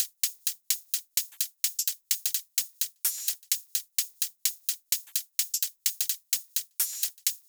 VDE 128BPM Renegade Drums 5.wav